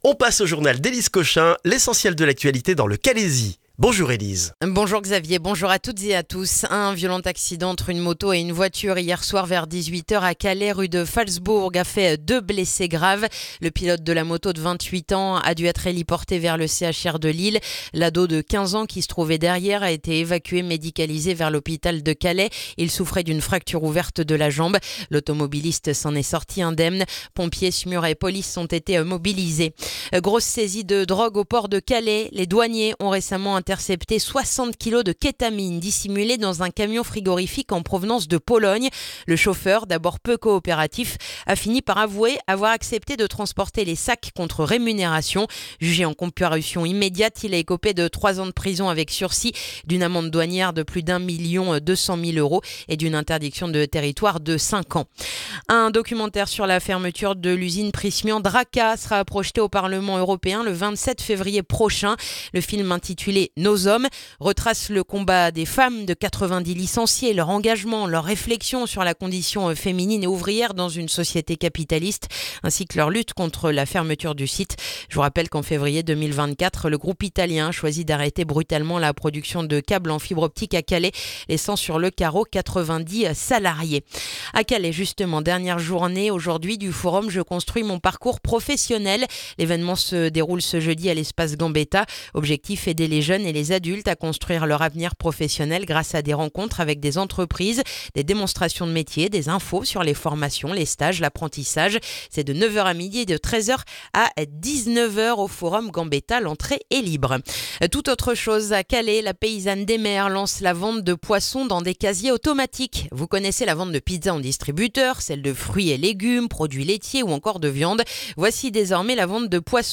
Le journal du jeudi 29 janvier dans le calaisis